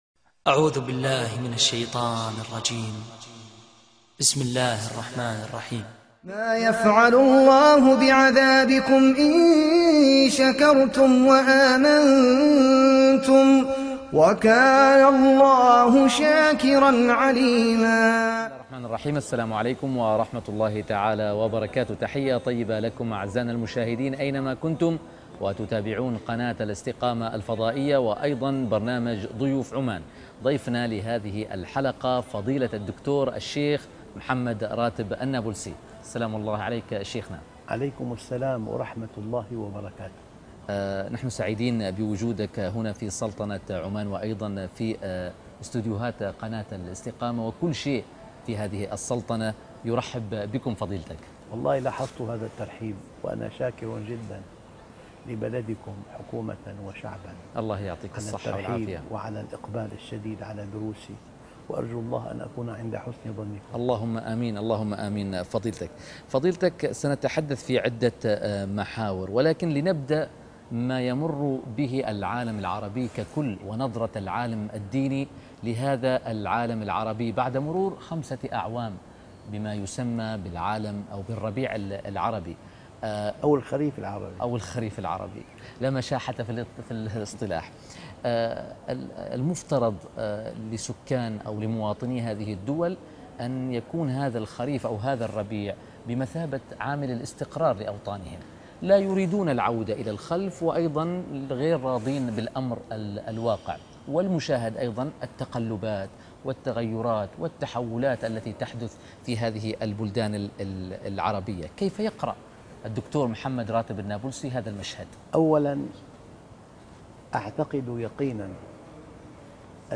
نظرة العالِم الدينية للعالَم العربي وما يمر به - لقاء خاص - الشيخ محمد راتب النابلسي